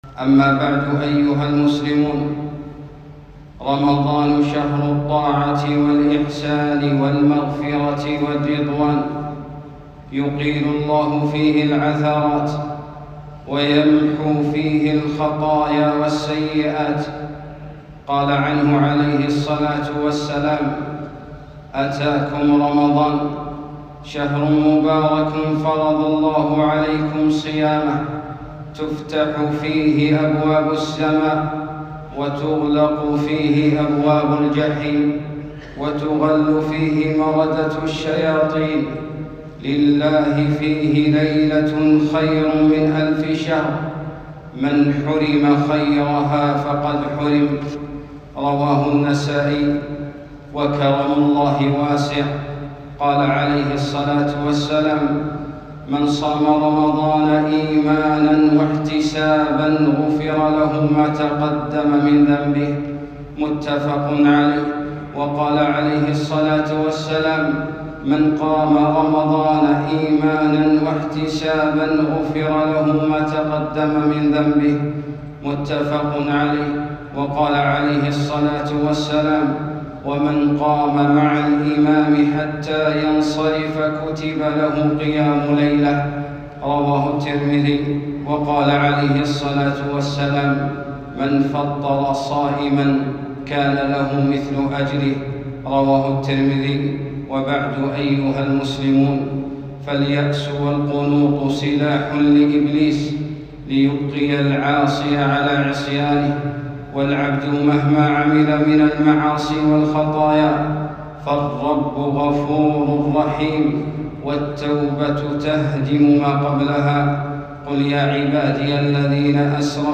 فضل رمضان - خطبة